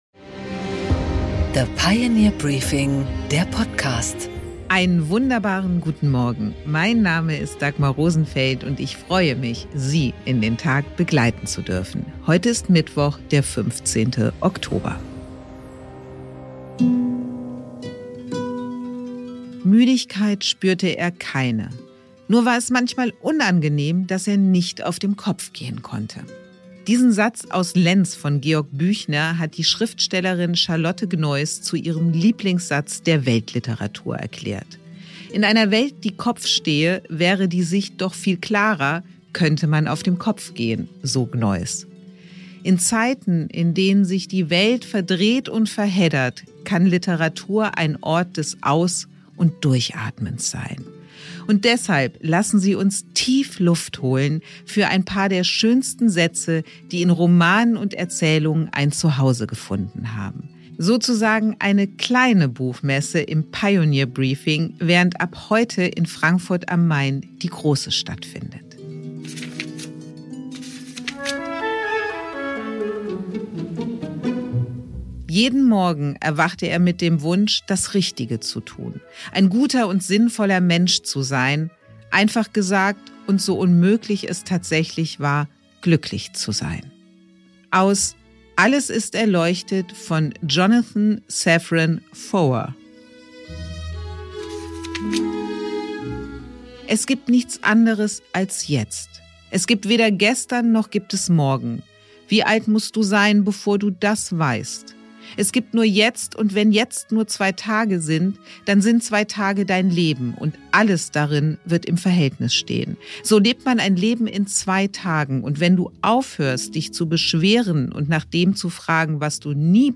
Interview mit Boris Pistorius